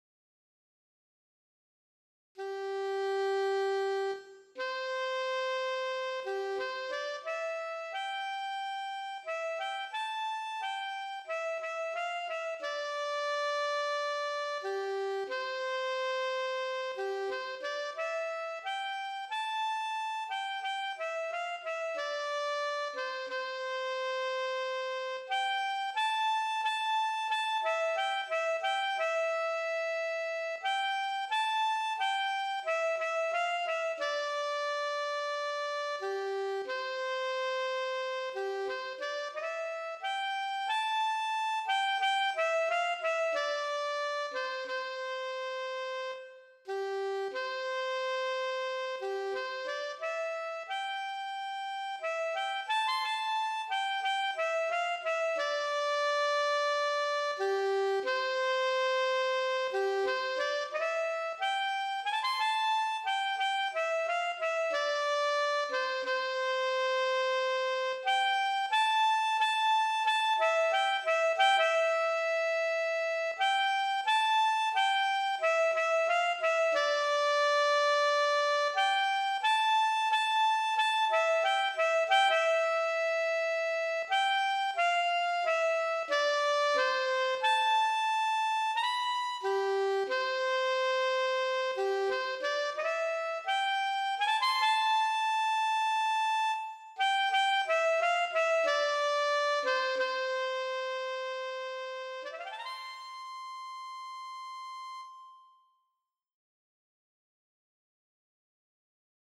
Voicing: Saxophone Solo